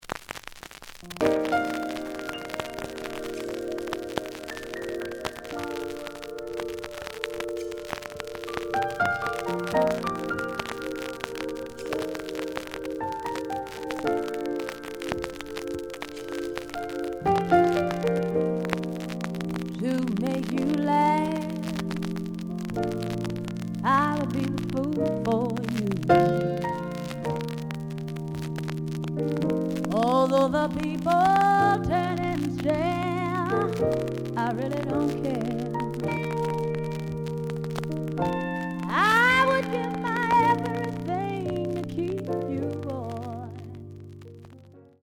The audio sample is recorded from the actual item.
●Genre: Funk, 70's Funk
Some noise on both sides.